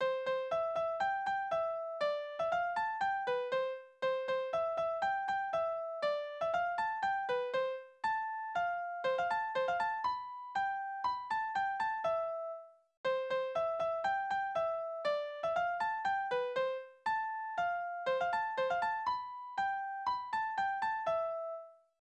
Ecossaise
Tonart: C-Dur Taktart: 2/4 Tonumfang: kleine Septime Besetzung: instrumental